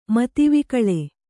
♪ mati vikaḷe